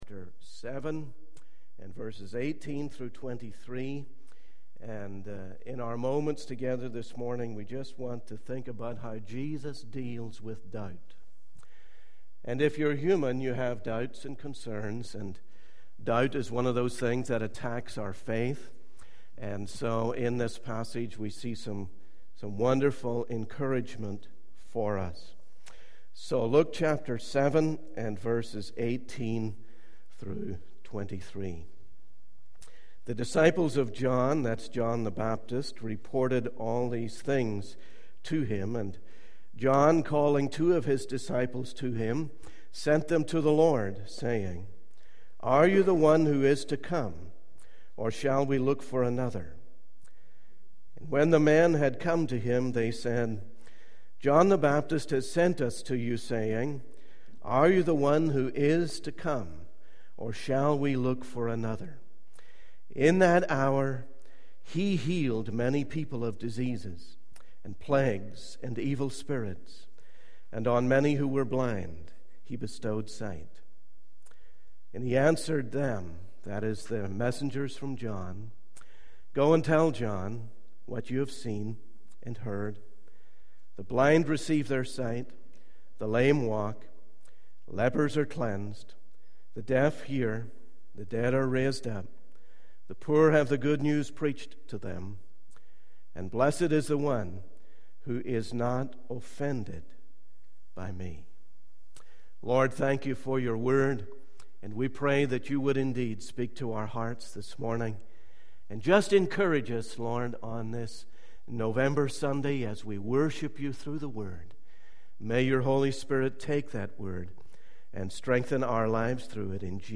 In this sermon, the pastor discusses how Jesus gives an immediate word of encouragement to his disciples. He emphasizes the importance of speaking the words that have been heard and seen, as well as keeping our focus on Jesus in times of doubt and struggle. The pastor also highlights the various ways that discouragement can affect us and reminds us to give our situations to God.